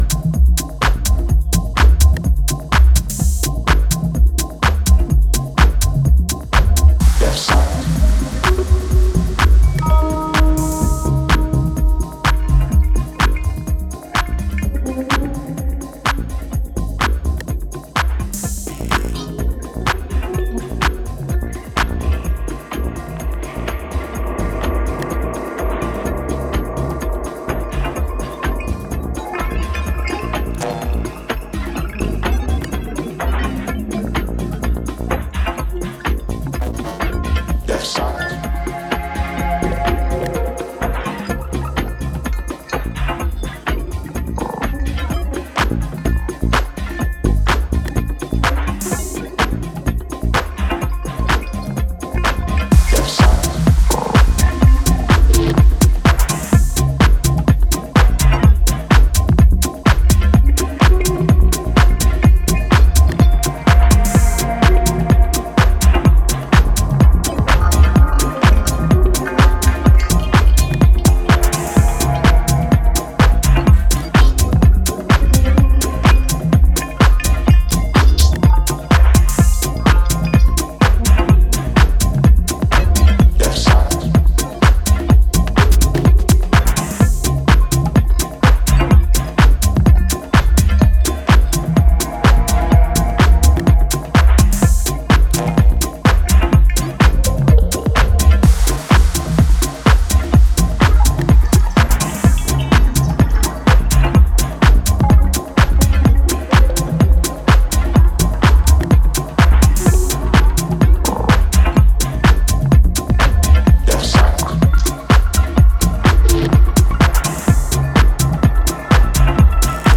minimal house music